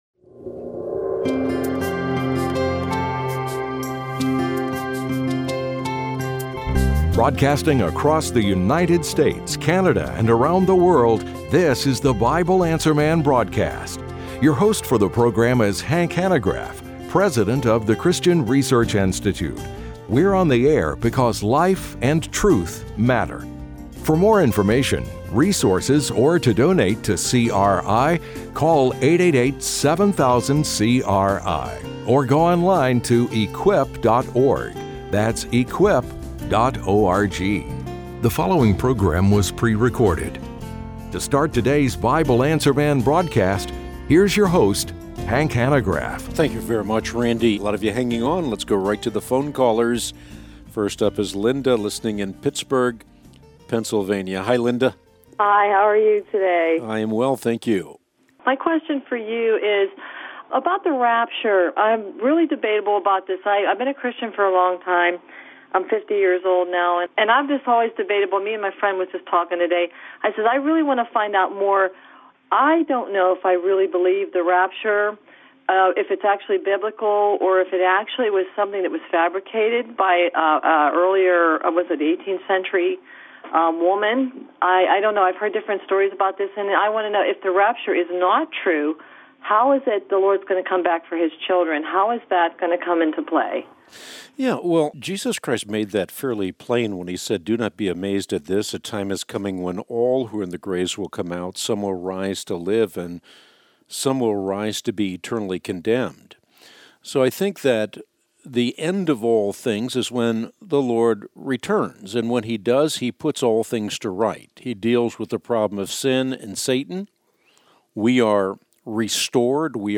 Q&A: The Rapture, Curse for Not Tithing, and Depression | Christian Research Institute